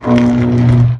elec_arch1.wav